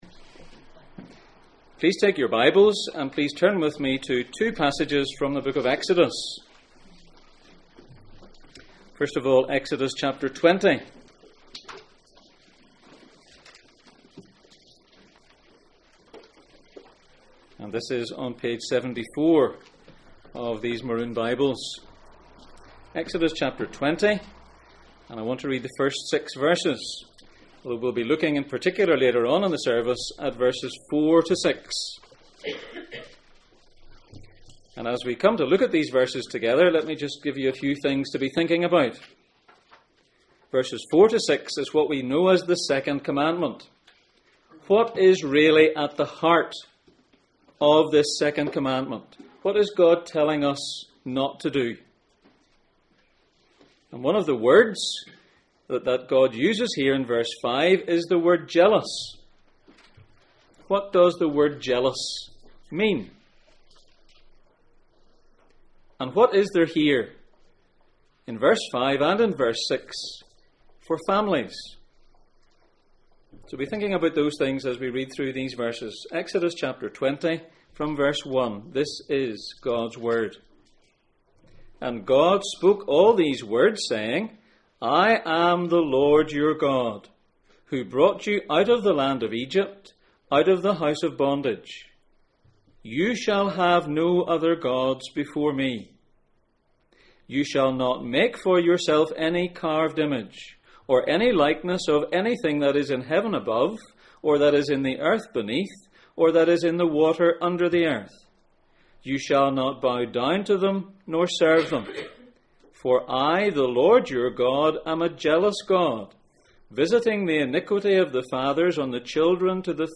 Gods instructions for life Passage: Exodus 20:1-4, Exodus 32:1-7, Deuteronomy 4:12, Colossians 1:15 Service Type: Sunday Morning